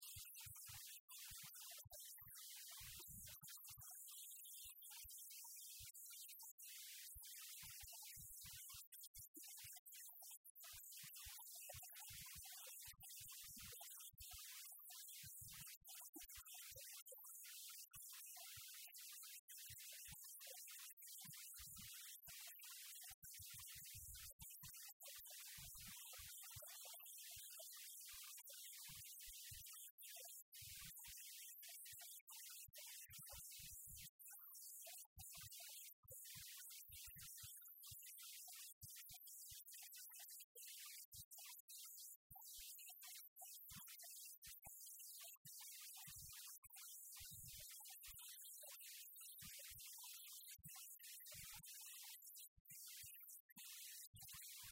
Um protocolo importante para o concelho macedense, considera Duarte Moreno, autarca local.
O protocolo de cooperação foi celebrado no passado sábado, no Museu de Arte Sacra de Macedo de Cavaleiros.